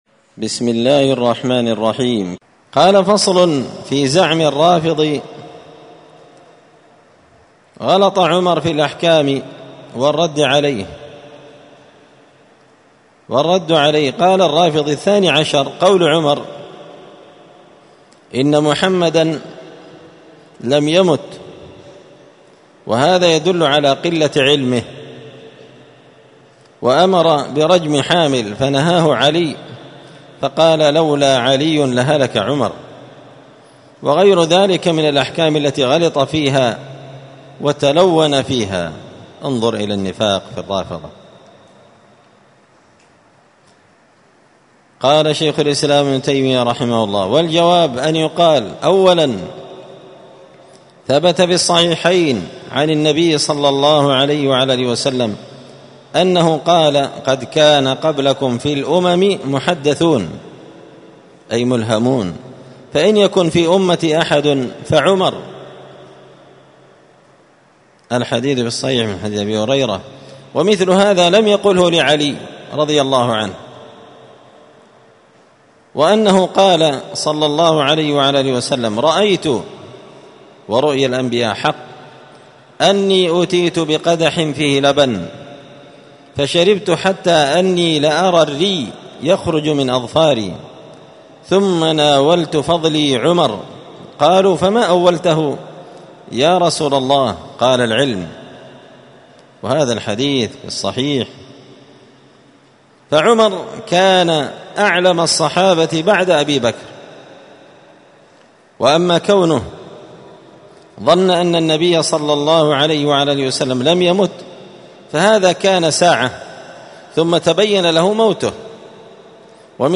*الدرس الرابع والثلاثون بعد المائتين (234) فصل في زعم الرافضي غلط عمر في الأحكام والرد عليه*